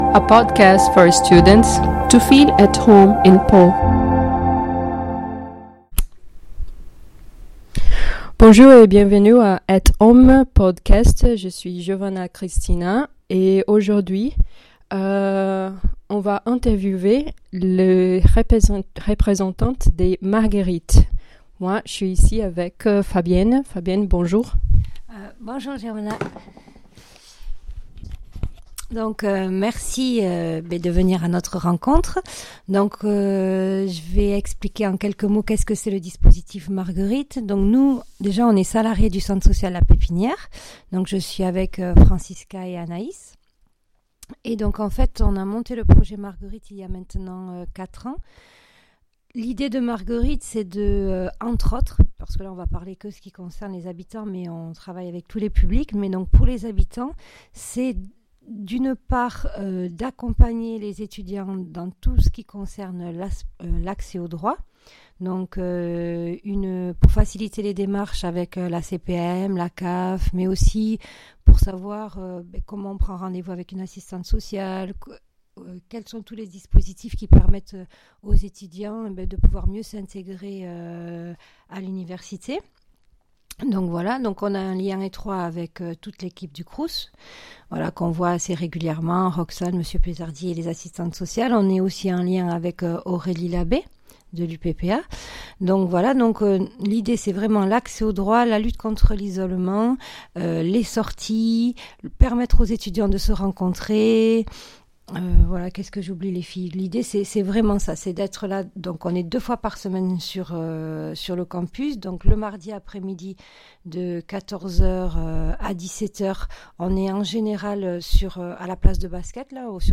At Home - Interview avec Marguerite - S01E02